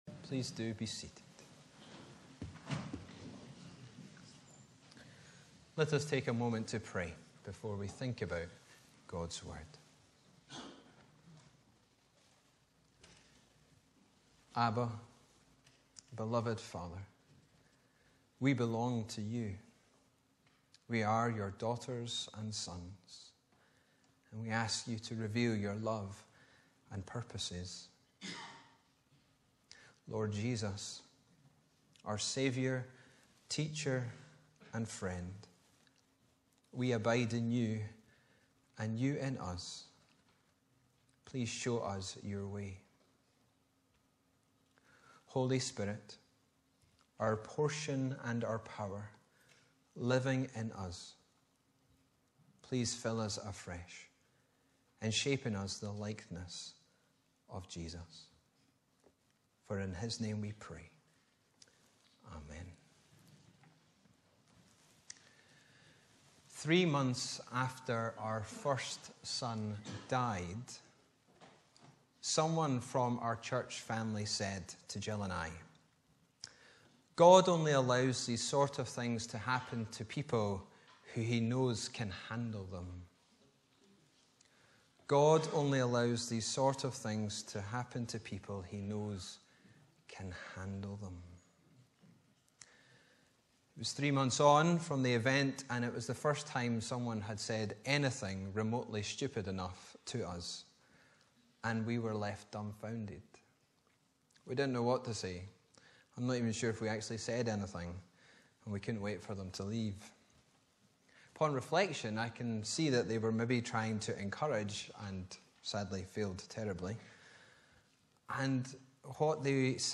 Apr 30, 2023 Hardships MP3 Subscribe to podcast Notes Sermons in this Series Preached on: Sunday 30th April 2023 The sermon text is available as subtitles in the Youtube video (the accuracy of which is not guaranteed).
Bible references: Joel 2:1-11 Location: Brightons Parish Church